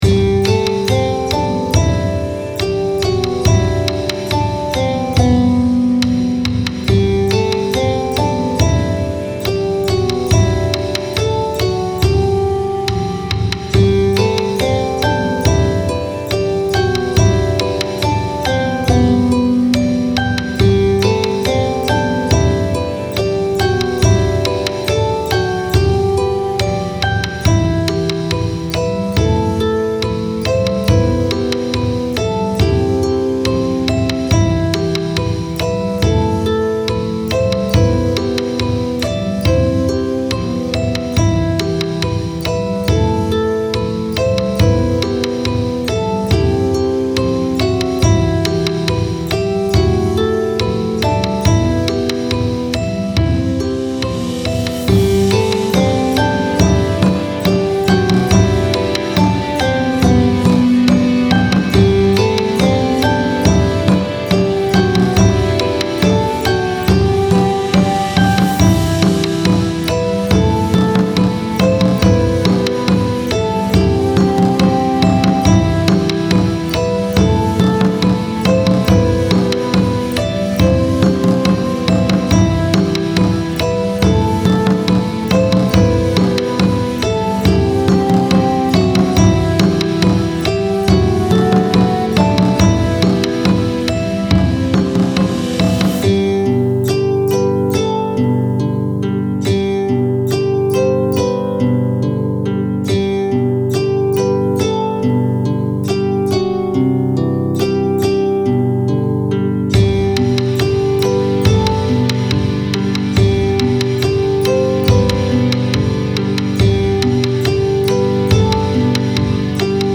琴と太鼓を使用した、スローテンポな和風の曲です。